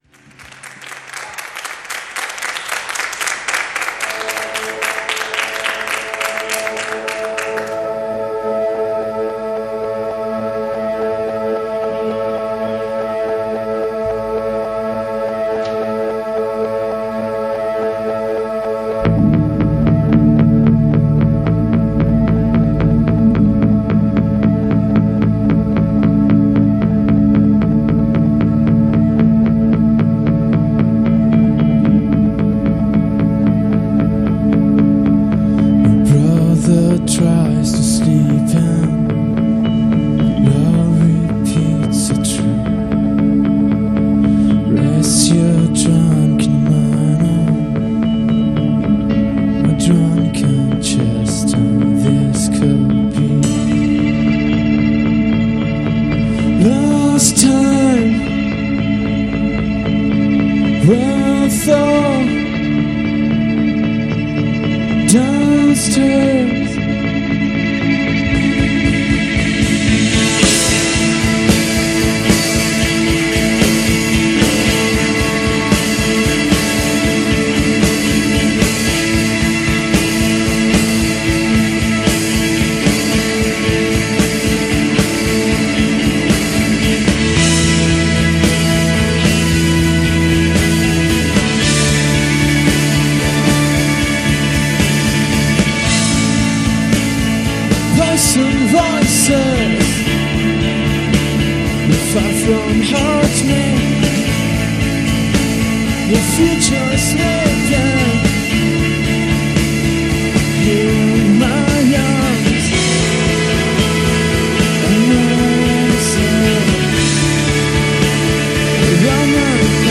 Guitar and vocals
Drums
Keyboards and programming
Bass